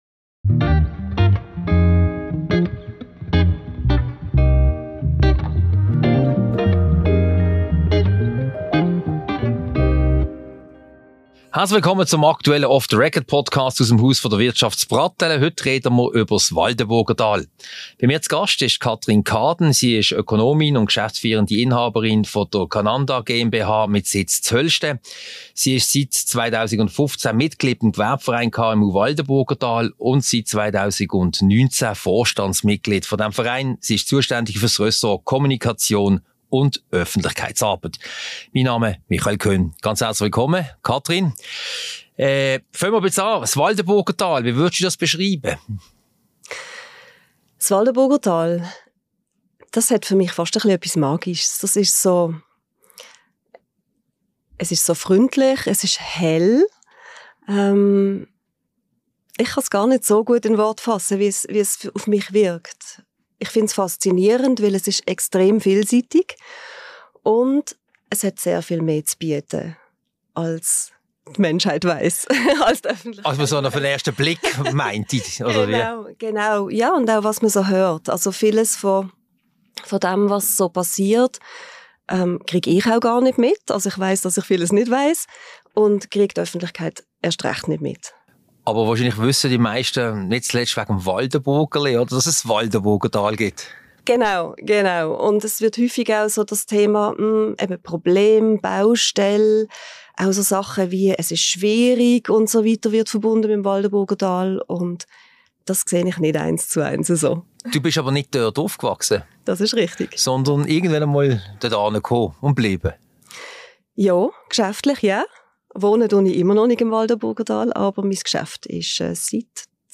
Diese Podcast-Ausgabe wurde als im Multimedia-Studio der IWF AG im Haus der Wirtschaft HDW aufgezeichnet.